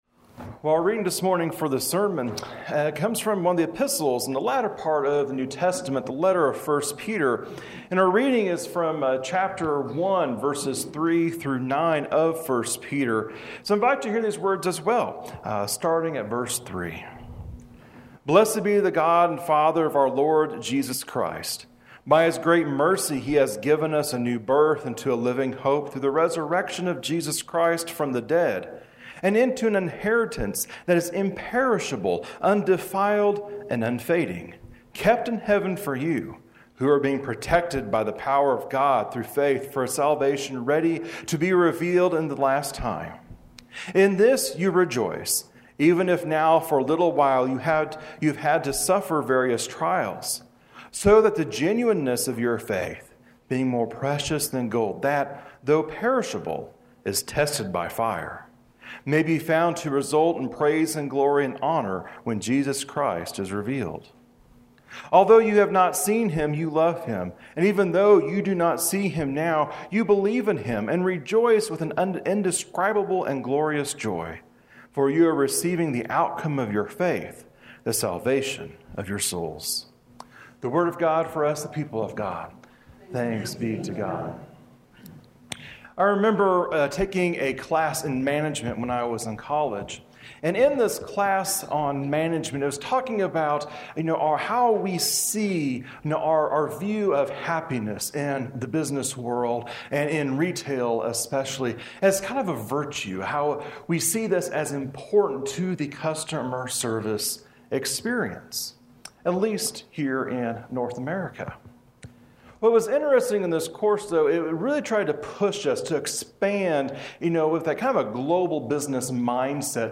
Sunday-Sermon-August-25.mp3